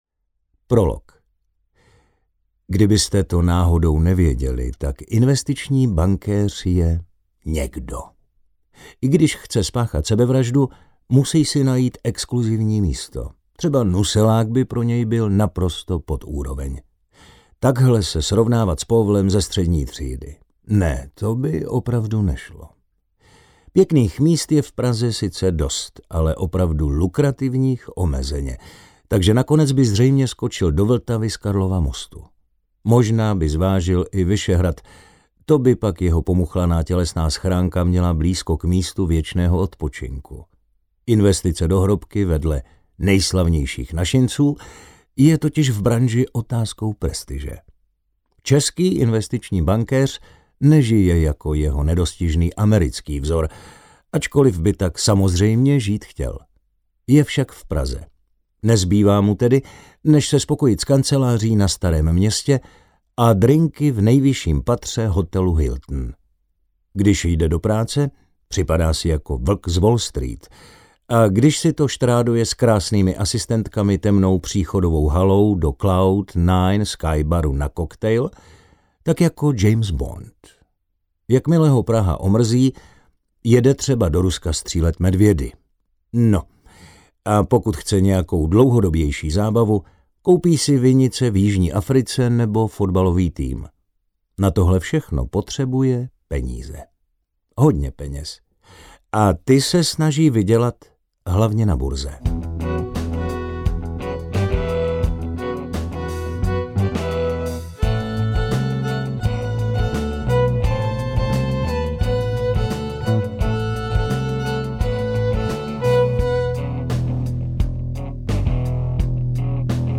Makléř audiokniha
Ukázka z knihy